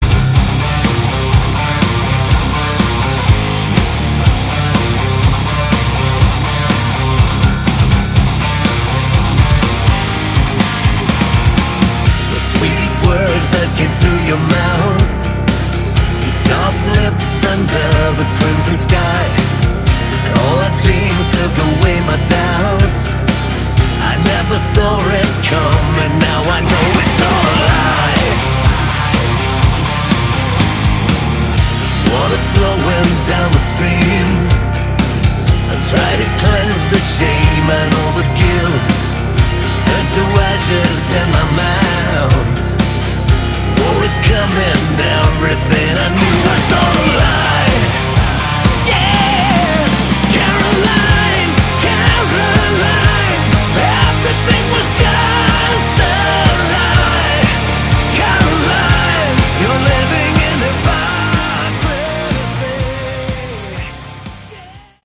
Category: Melodic Hard Rock
lead and background vocals
lead guitar, bass, keyboards, background vocals
drums